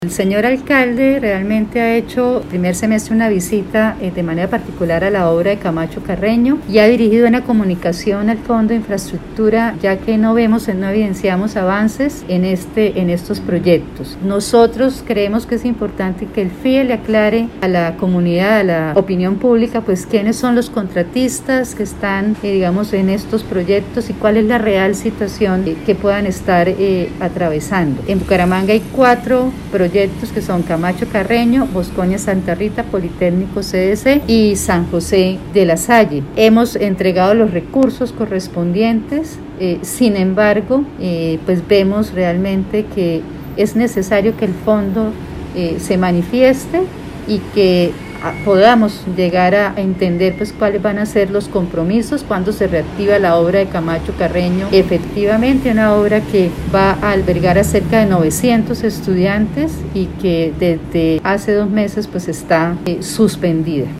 Audio: Ana Leonor Rueda, secretaria de Educación